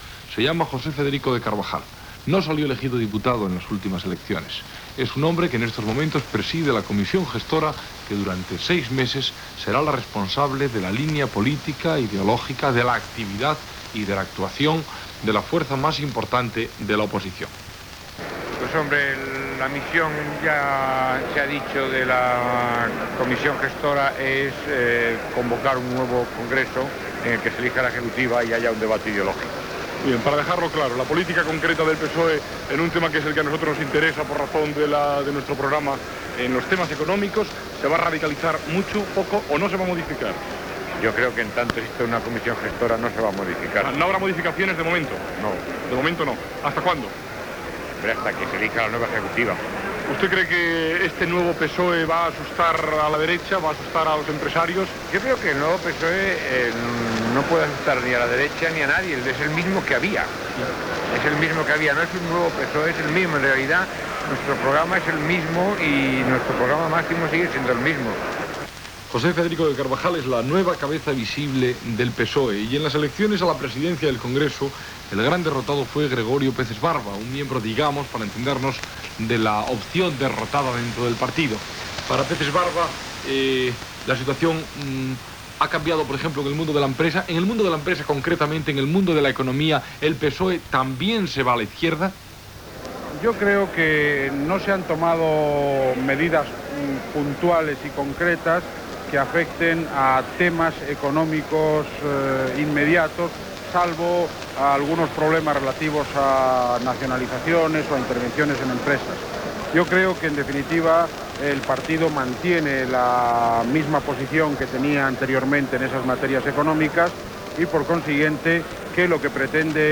Gènere radiofònic Informatiu